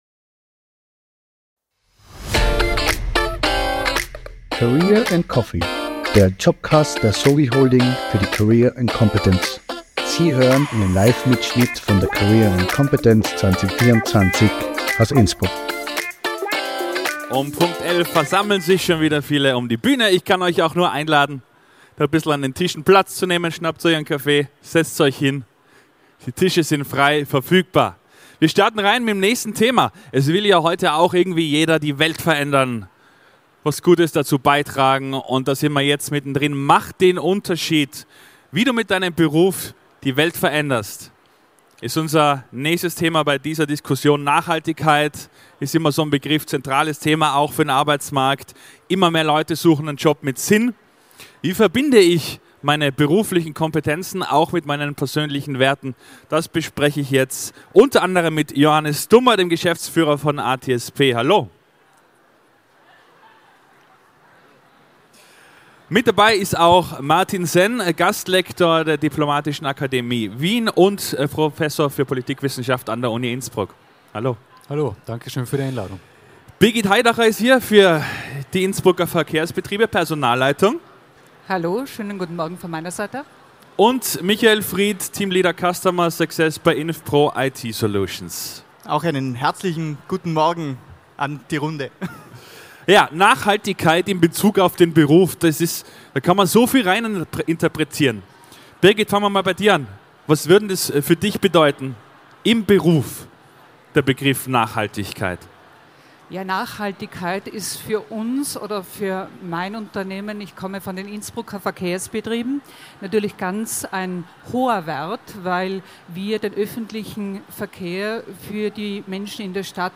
Livemitschnitt von der career & competence am 24. April 2024 im Congress Innsbruck.